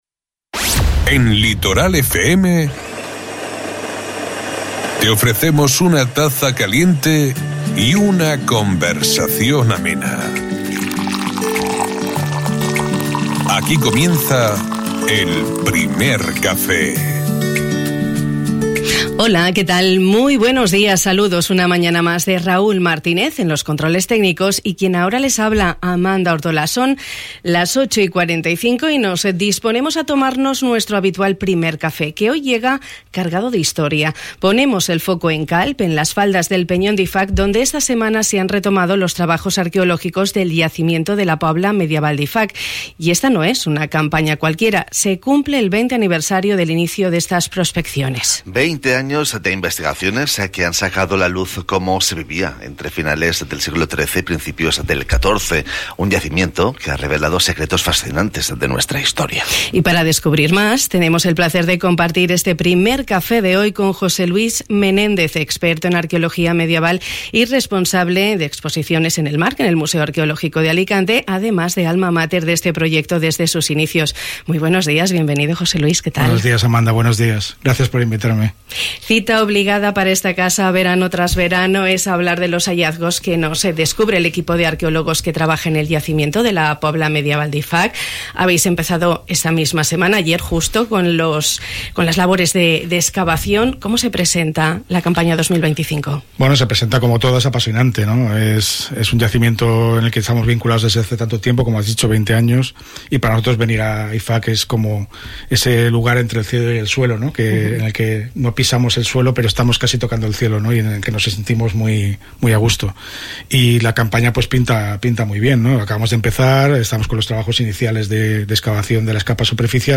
Esta mañana nos hemos tomado un Primer Café cargado de historia.